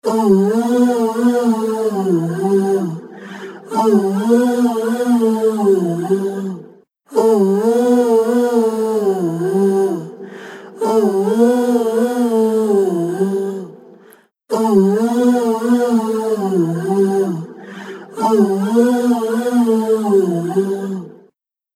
TriceraChorusは、サウンドに極上の豊かさと空間的な奥行きをもたらす、洗練されたコーラス・ソリューションです。
TriceraChorus | Vocals | Preset: Lust Life
TriceraChorus-Eventide-Vocals-Lust-Life.mp3